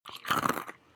hunger_ng_eat.1.ogg